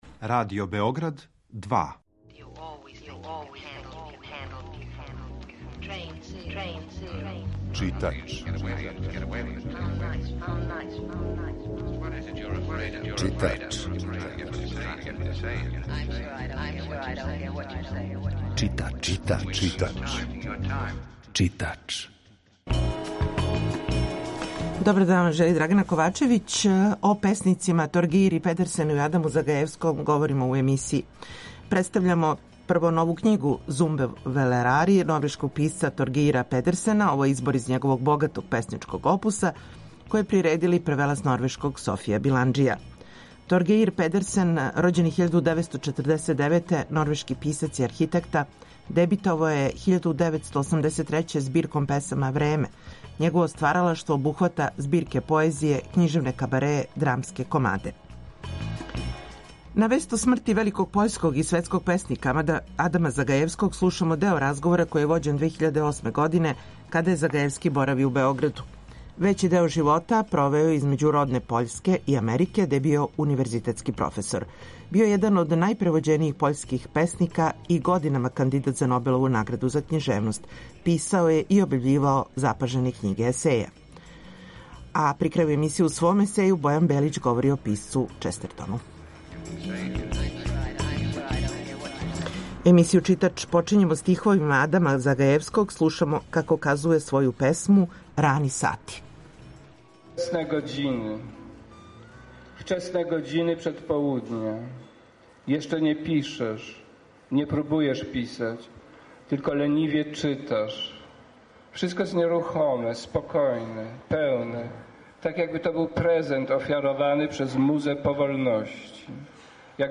На вест о смрти великог пољског и светског песника Адама Загајевског, слушамо део разговора који је вођен 2008. године када је Загајевски боравио у Београду.